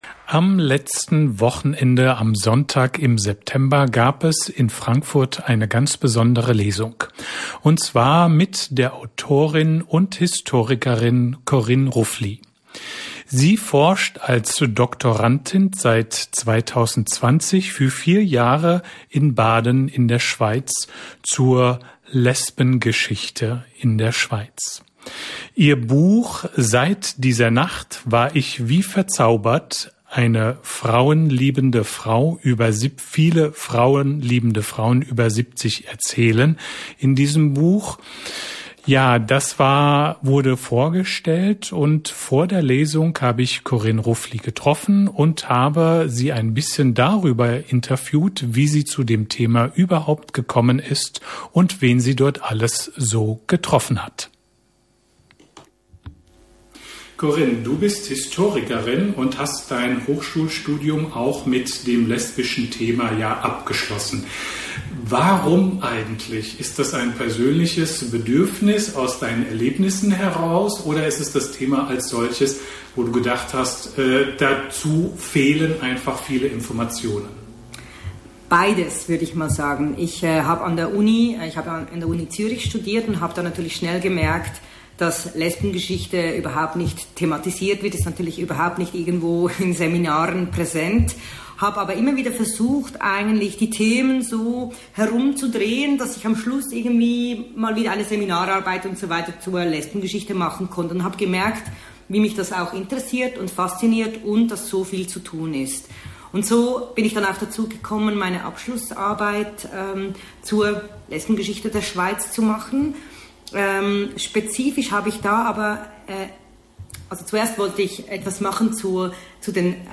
1262_lesung.mp3